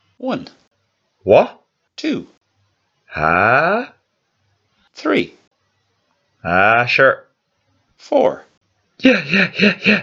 Irish Interjection Sounds
Irish people even have a few unique interjection sounds of their own.
irish-interjections.mp3